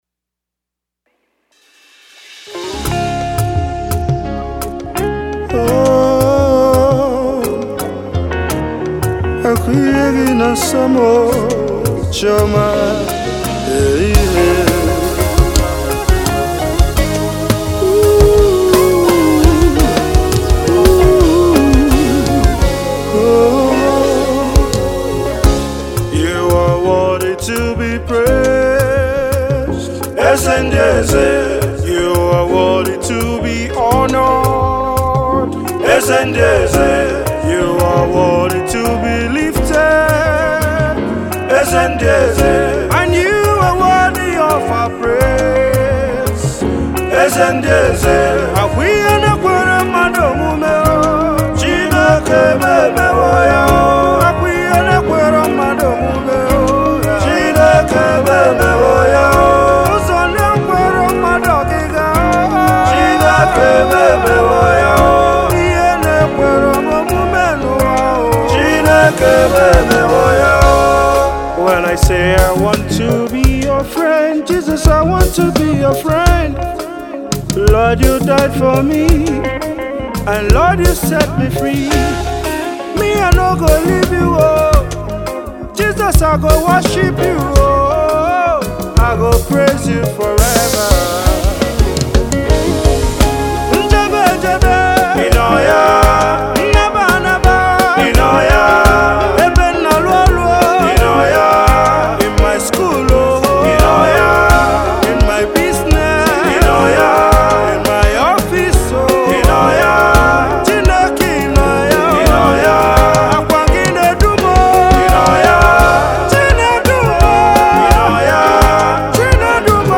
thanksgiving song